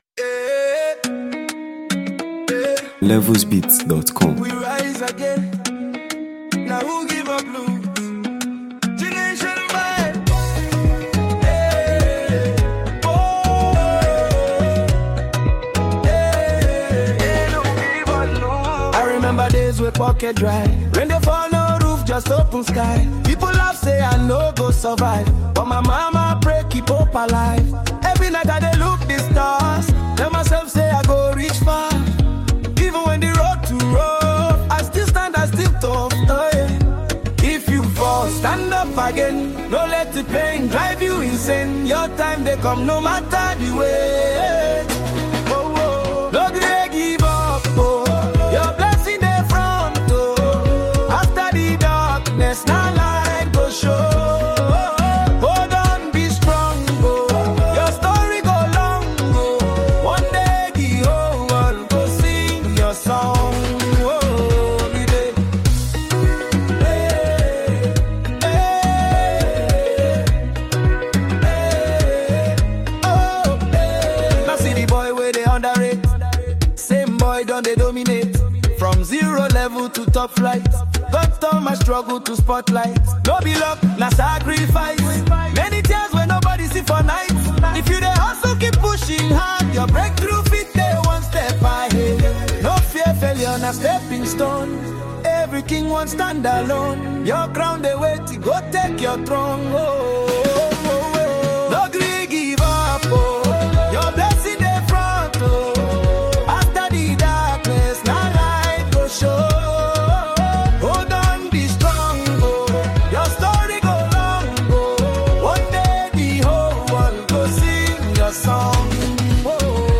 emotional delivery
seasoned vocals
smooth production